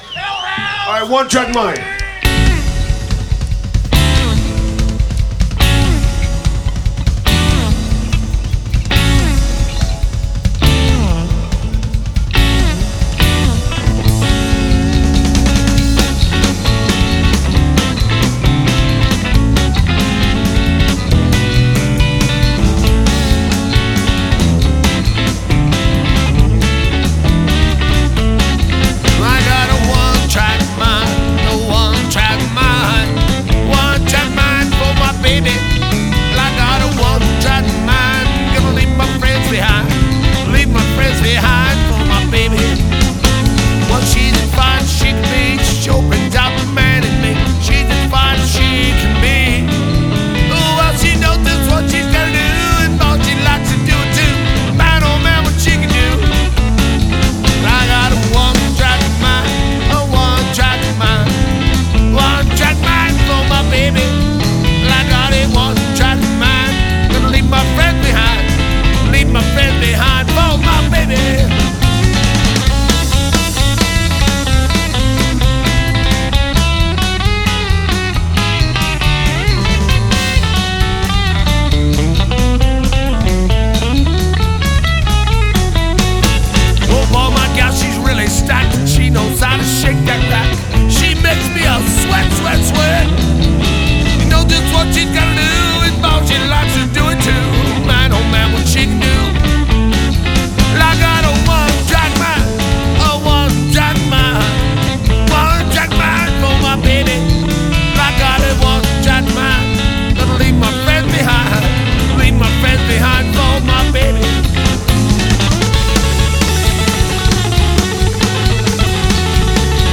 Awesome fun mix of just good ol' Rockin' music.